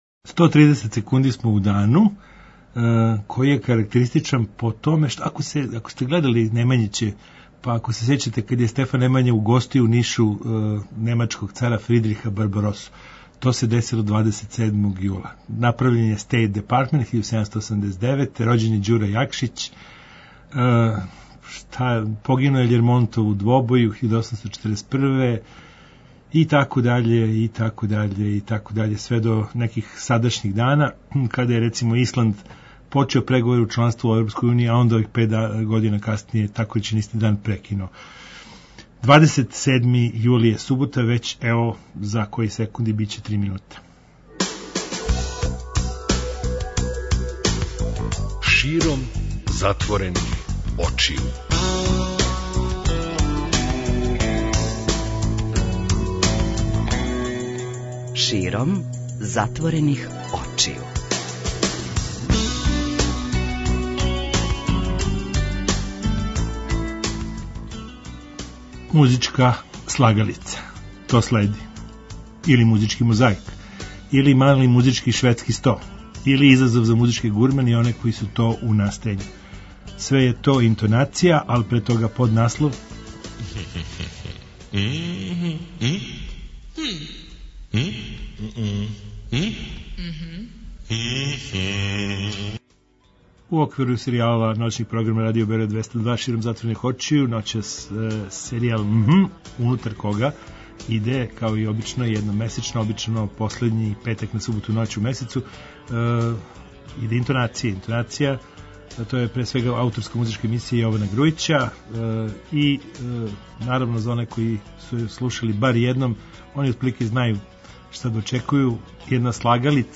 Музичка слагалица. Музички мозаик.